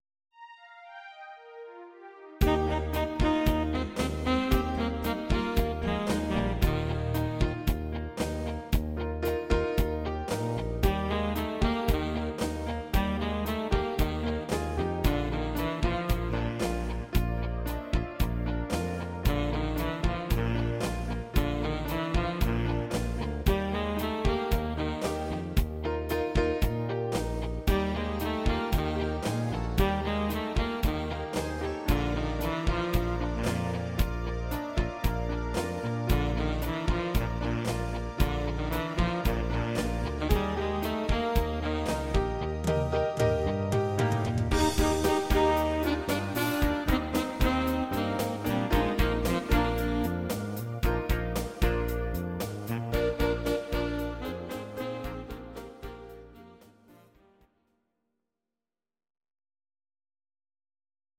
Audio Recordings based on Midi-files
German, Traditional/Folk, Volkst�mlich